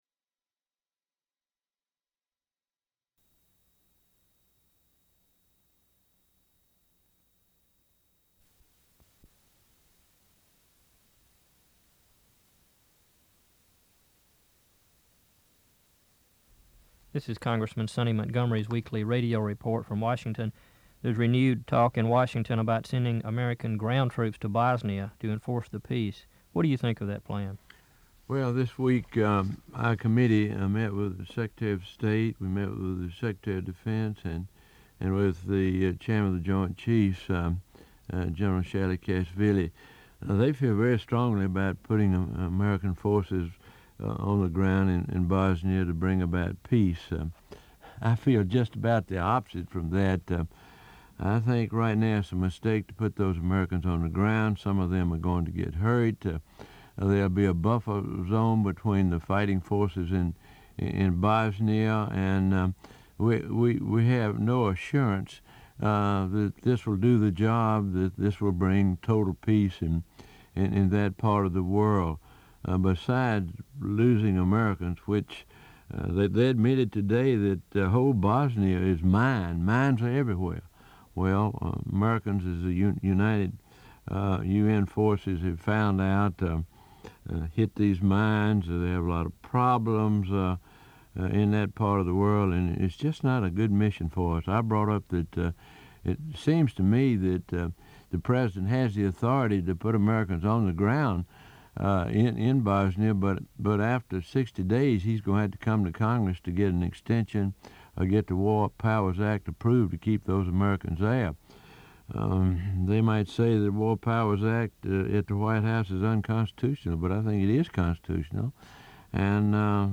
Weekly Radio Addresses